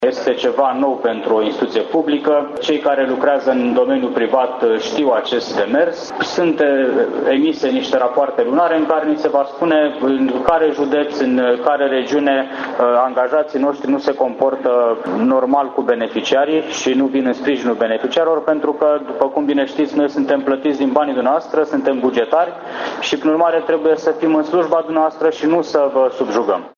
Prezent la Slobozia în cadrul caravanei ,,PNDR vine în satul tău“, directorul general George Octavian Turtoi a precizat că Agenţia urmăreşte verificarea modului de lucru a funcţionarilor cu aplicanţii de proiecte şi beneficiarii de finanţări nerambursabile: